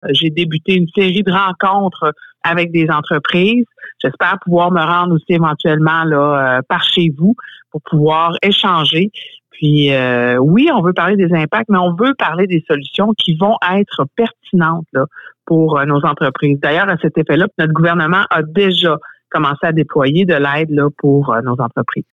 En entrevue à la radio CFMF cette semaine, elle indique avoir entamé un travail sur le terrain pour entendre les préoccupations à ce sujet :